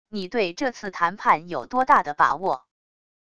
你对这次谈判有多大的把握wav音频生成系统WAV Audio Player